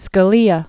(skə-lēə), Antonin 1936-2016.